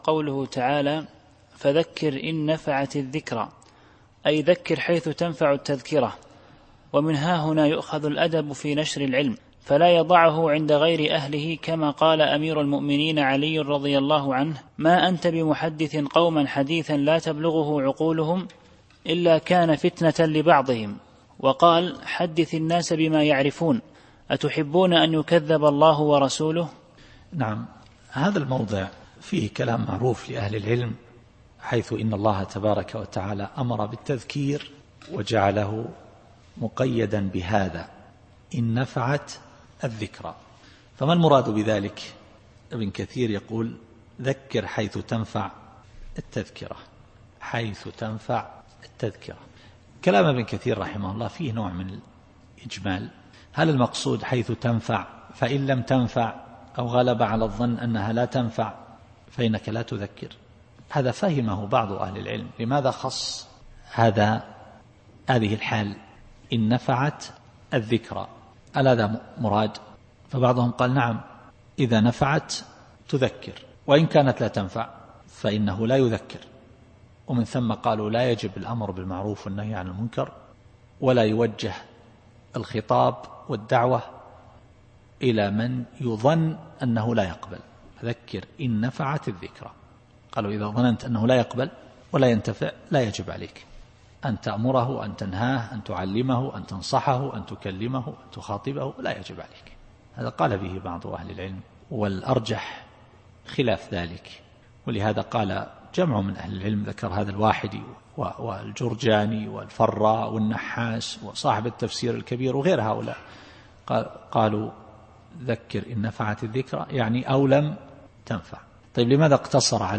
التفسير الصوتي [الأعلى / 9]